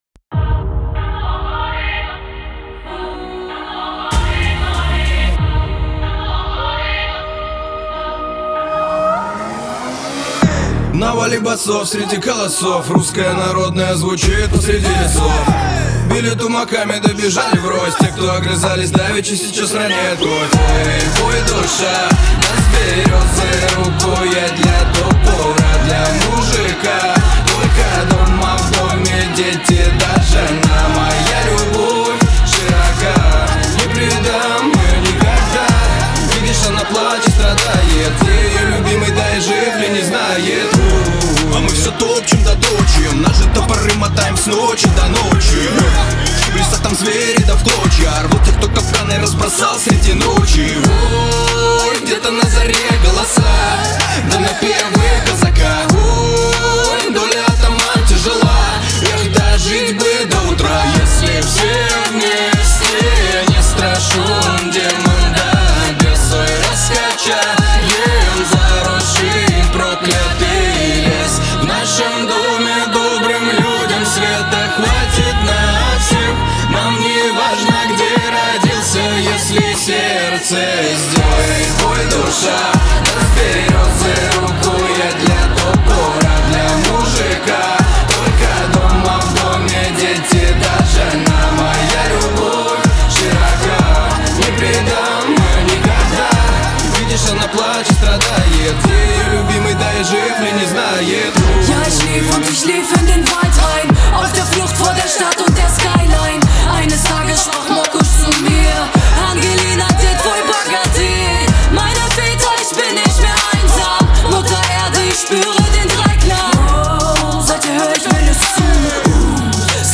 Русский рэп